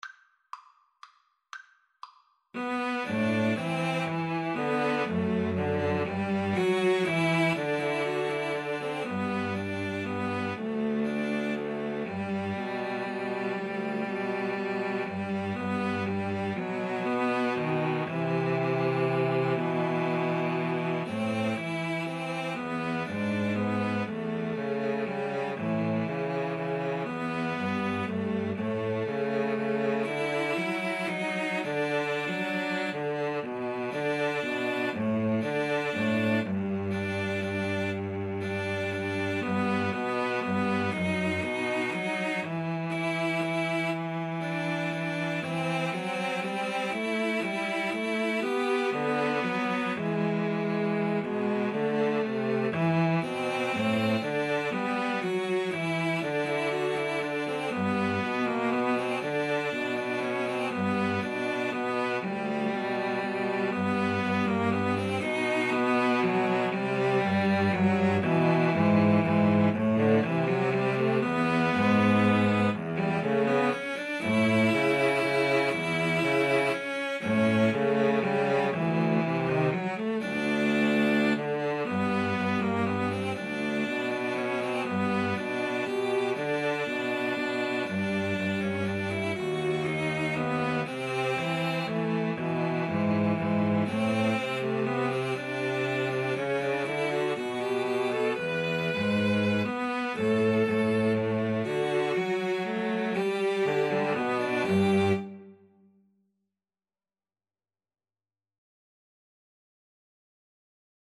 3/4 (View more 3/4 Music)
= 120 Tempo di Valse = c. 120
Cello Trio  (View more Intermediate Cello Trio Music)